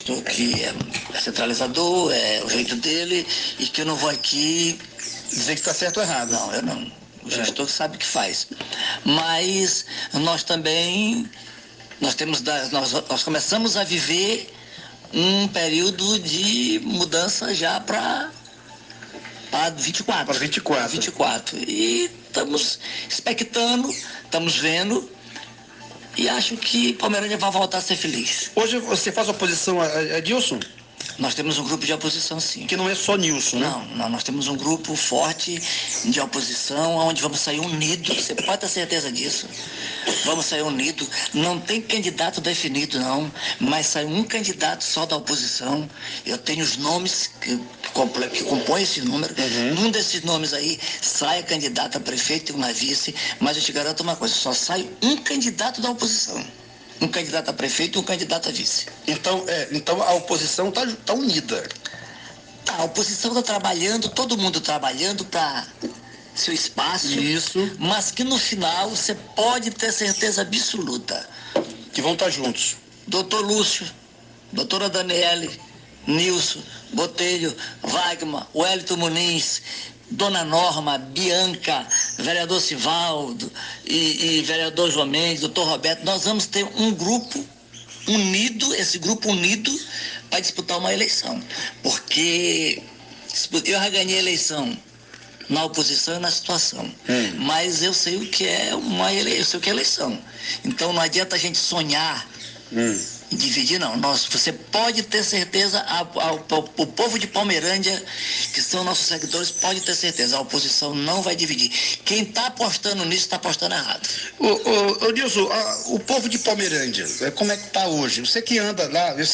O ex-prefeito de Palmeirândia, Nilson Garcia, afirmou durante uma entrevista a uma rádio local nesta quarta-feira (5) que a oposição no município não se dividirá e caminhará unida para derrotar o atual prefeito, Edilson da Alvorada (Republicanos), nas eleições de 2024.